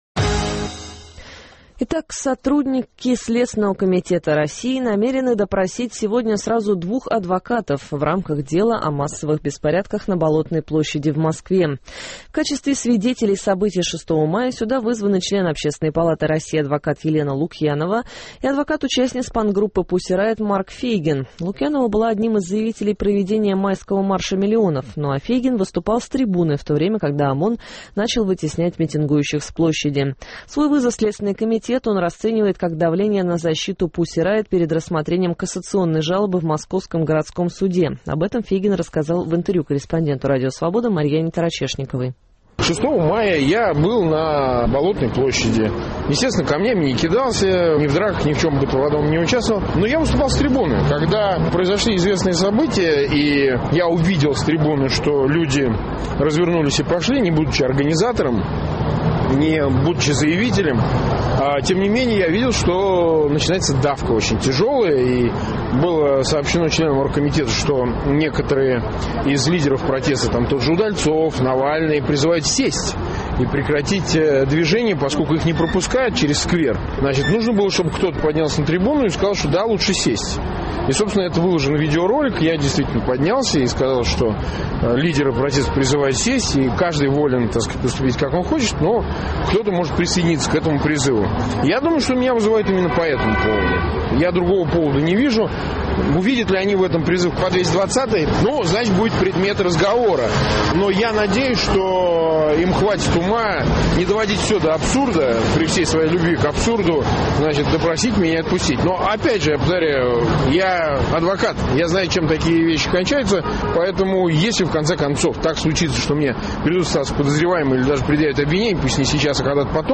Интервью с Фейгиным